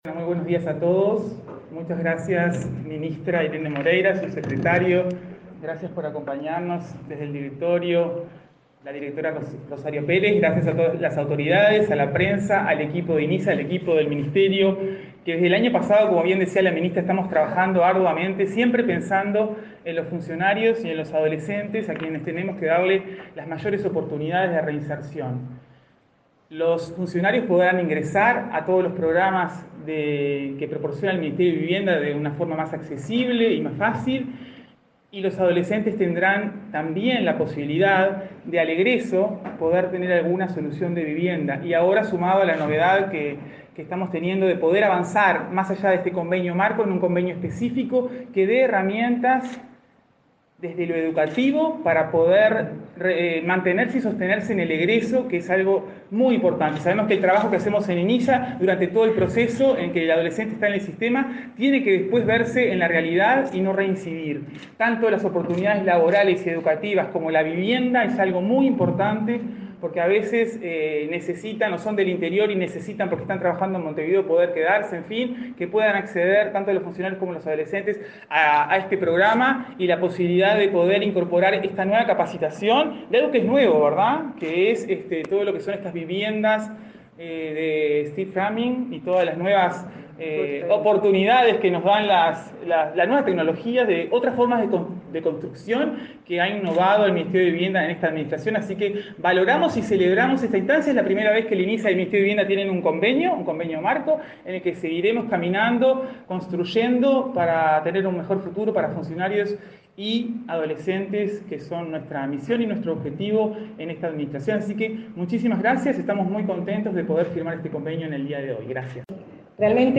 Palabras de la presidenta de Inisa y de la ministra de Vivienda